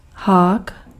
Ääntäminen
IPA: [kʁɔ.ʃɛ]